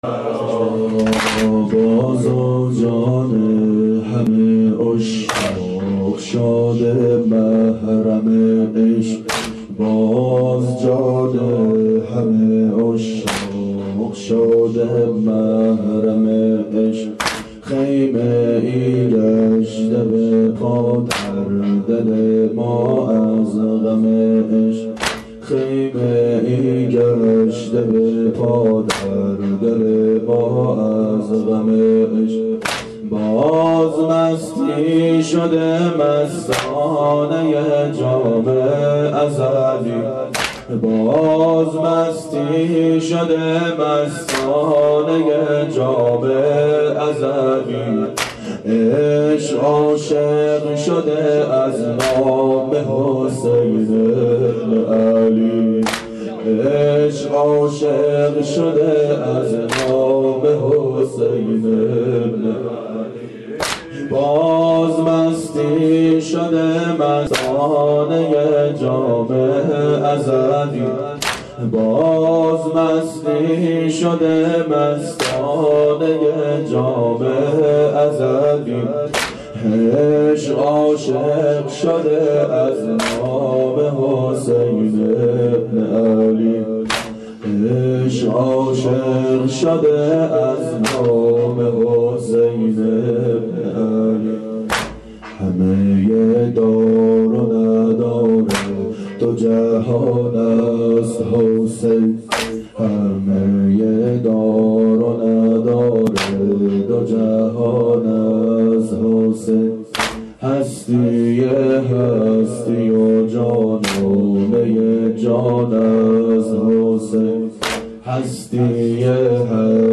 شب عاشورا 1389 هیئت عاشقان اباالفضل علیه السلام